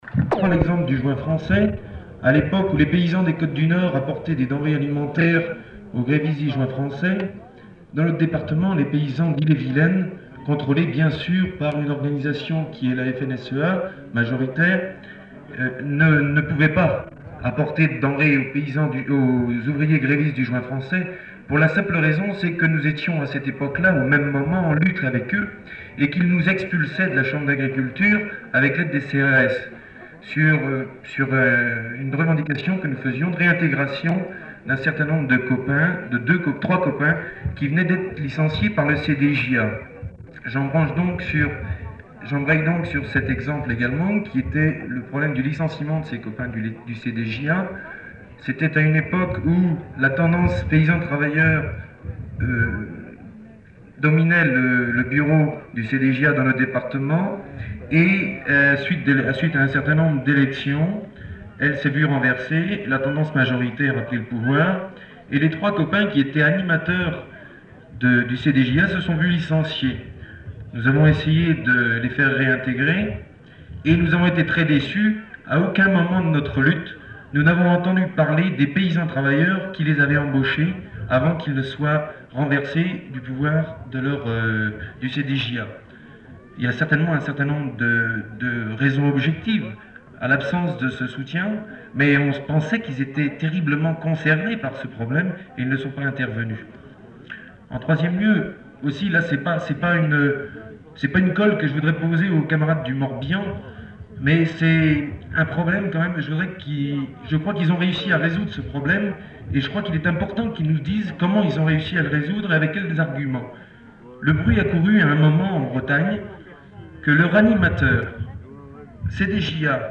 Lieu : [sans lieu] ; Aveyron
Genre : parole
Notes consultables : Les allocuteurs ne sont pas identifiés.